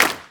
DrClap20.wav